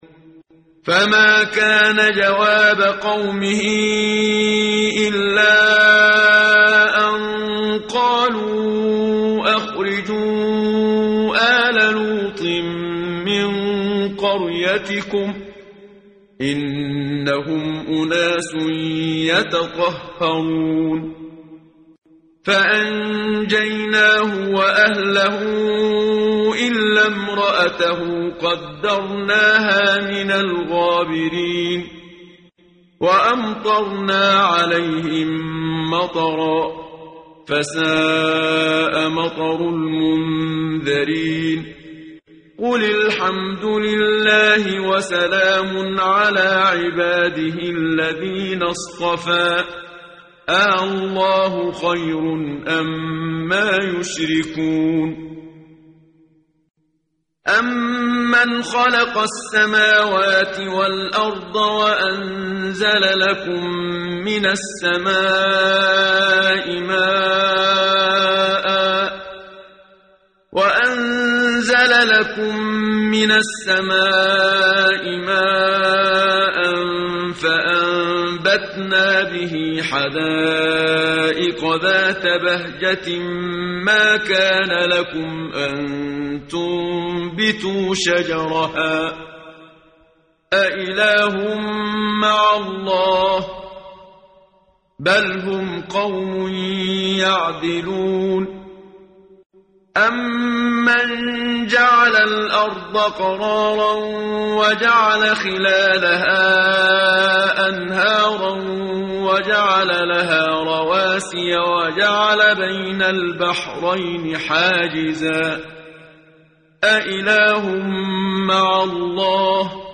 قرائت قرآن کریم ، صفحه 382، سوره مبارکه النمل آیه 56 تا 63 صدای استاد صدیق منشاوی.